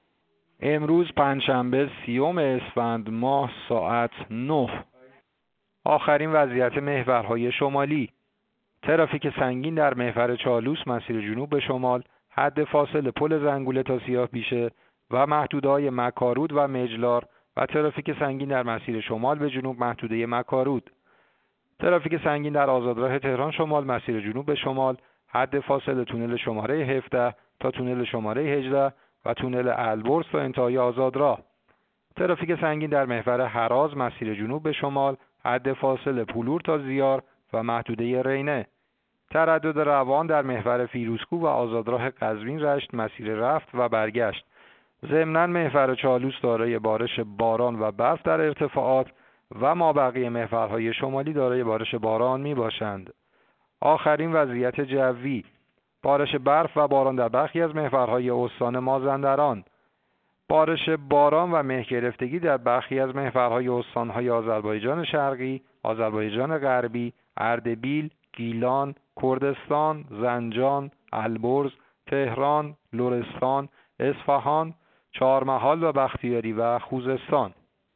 گزارش رادیو اینترنتی از آخرین وضعیت ترافیکی جاده‌ها ساعت ۹ سی ام اسفند